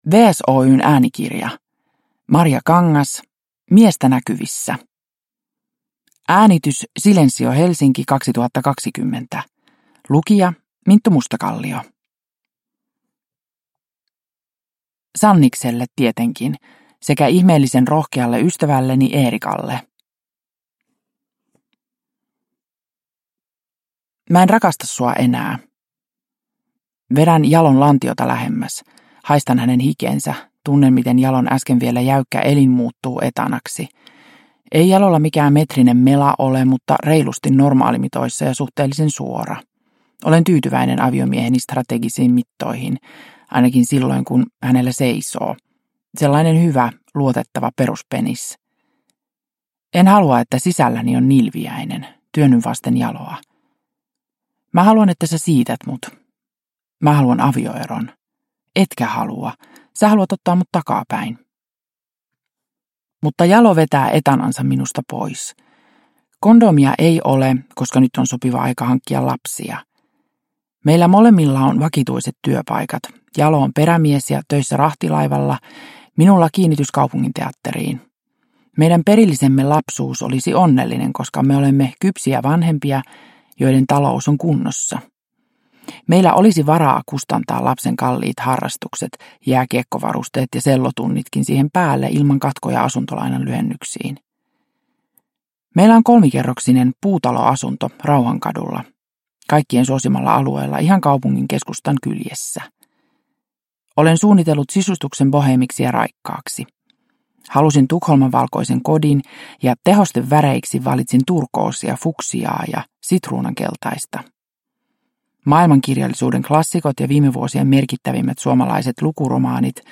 Miestä näkyvissä – Ljudbok – Laddas ner
Uppläsare: Minttu Mustakallio